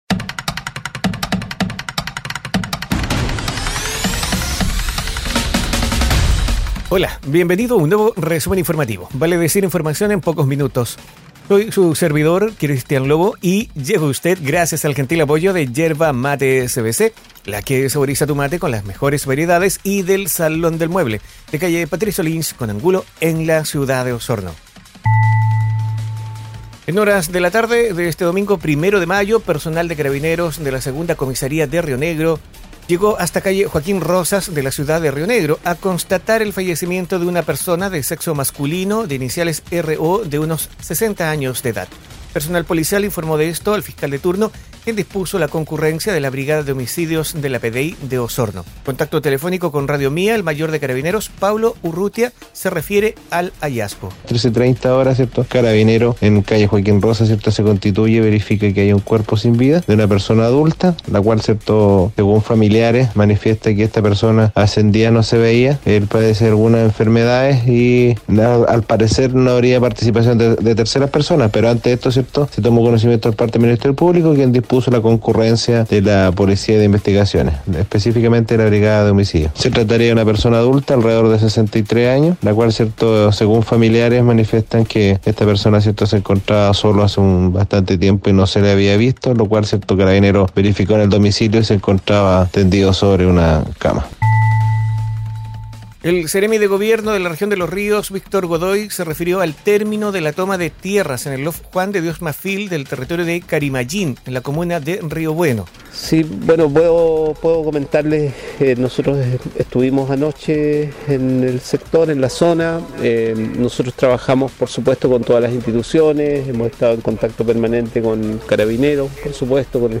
Resumen informativo es un audio podcast con una decena informaciones en pocos minutos, enfocadas en la Región de Los Lagos,